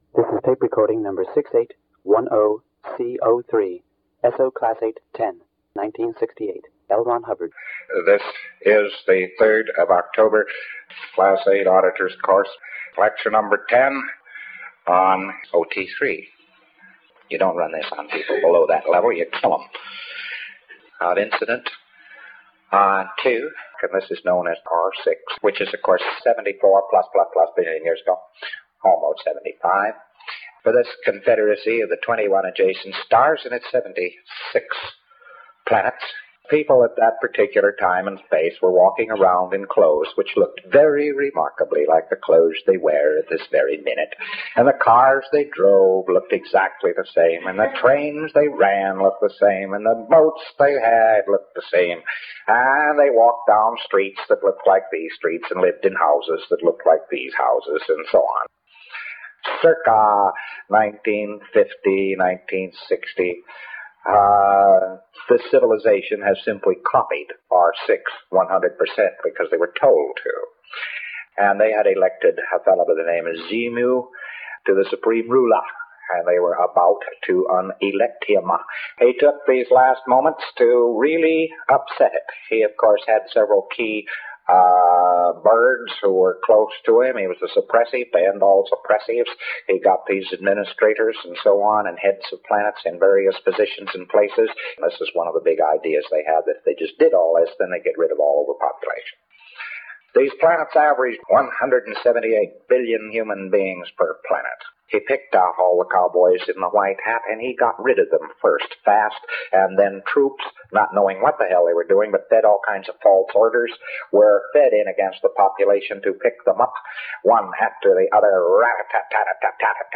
The Origin of Engrams - narrated by L.Ron Hubbard